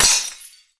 poly_explosion_crystal.wav